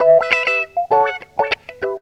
GTR 50 EM.wav